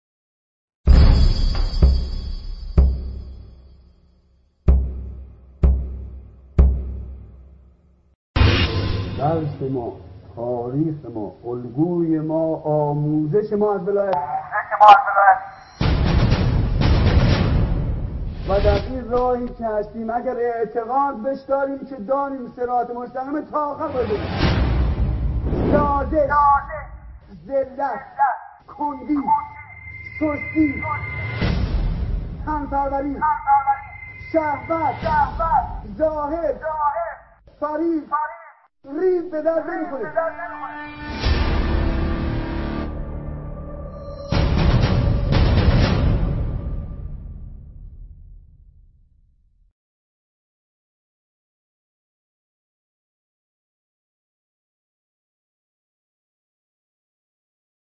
شهید ابراهیم همت، در سخنرانی خود در میان لشکریانش، اعتقاد به ولایت را صراط مستقیم خواند و دوری از آن را بیراهه عنوان کرد.